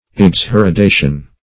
Search Result for " exheredation" : The Collaborative International Dictionary of English v.0.48: Exheredation \Ex*her`e*da"tion\, n. [L., exheredatio: cf. F. exh['e]r['e]dation.]
exheredation.mp3